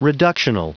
Prononciation du mot reductional en anglais (fichier audio)
Prononciation du mot : reductional